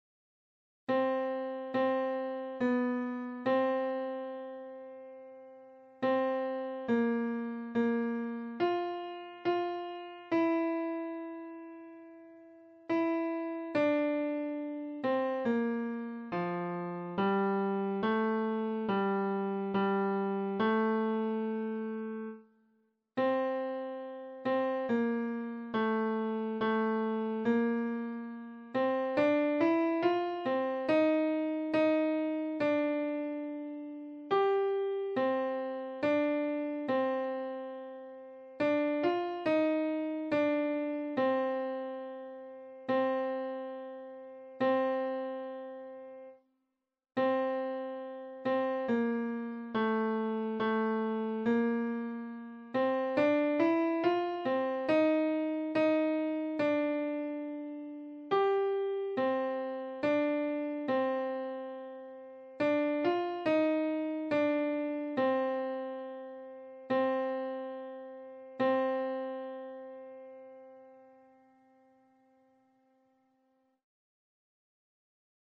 • Alto:
Number of voices: 5vv   Voicing: SATTB
Genre: SecularMadrigal
Instruments: A cappella
The_Silver_Swan_Alto.mp3